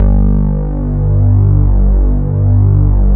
16 BASS   -L.wav